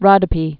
(rŏdə-pē, rŏ-dō-)